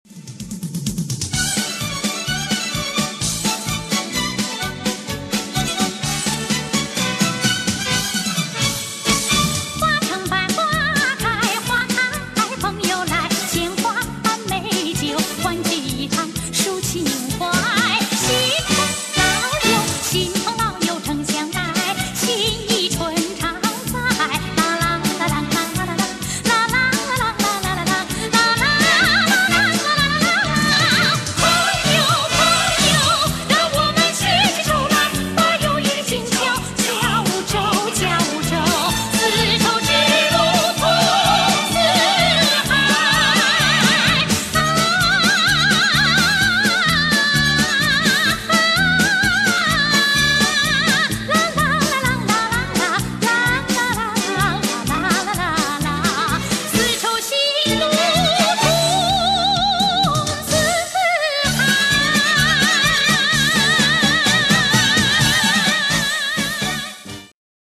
他在保留广东音乐韵味的基础上，适当增添当年的“新潮音乐元素”。
歌曲中多处要求歌者用近似花腔的技术处理，唱起来很吃功夫。